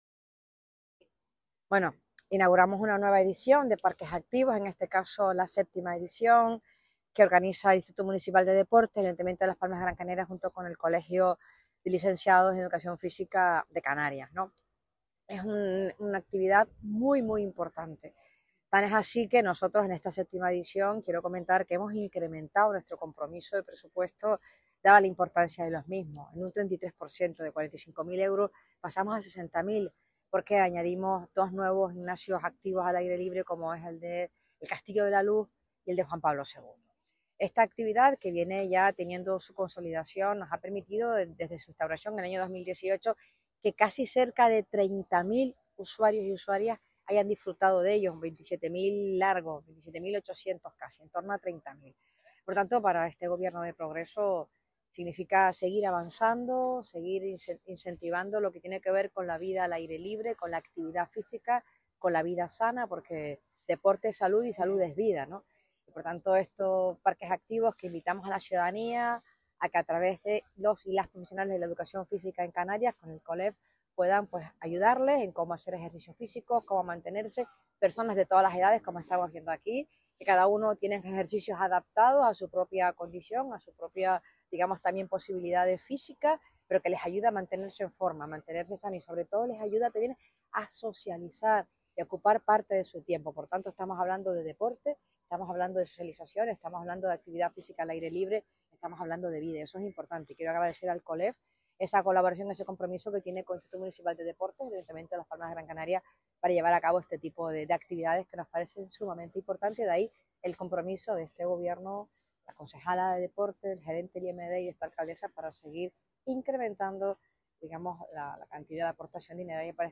Alcaldesa-Darias_Presentacion-Parques-Activos.mp3